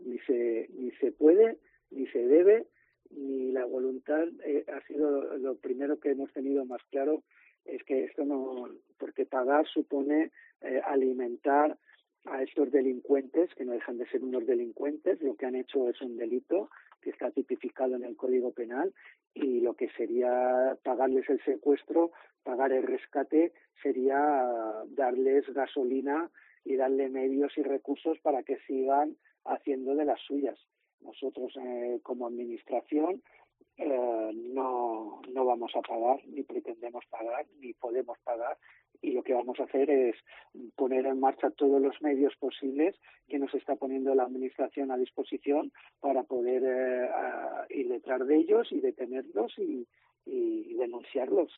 Juan Antonio Amengual, alcalde de Calvià